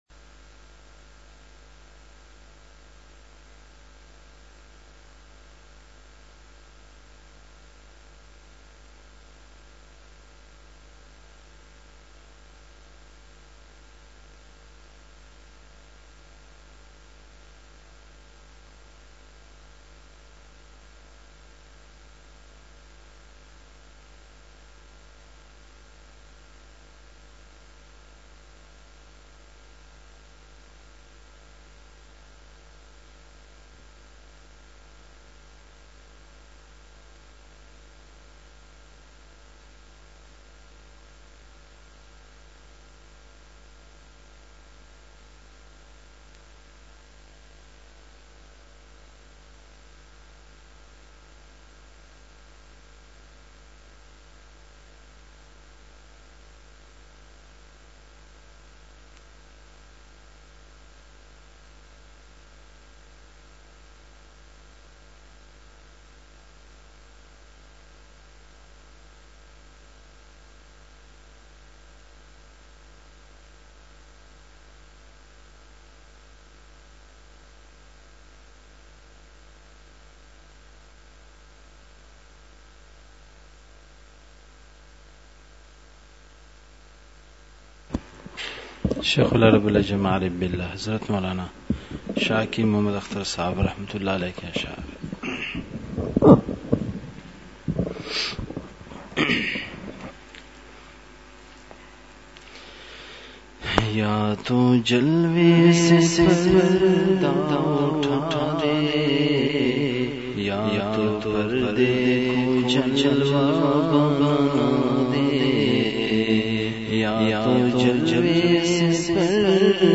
*مقام:مسجد اختر نزد سندھ بلوچ سوسائٹی گلستانِ جوہر کراچی*
04:05) اشعار پڑھے گئے۔۔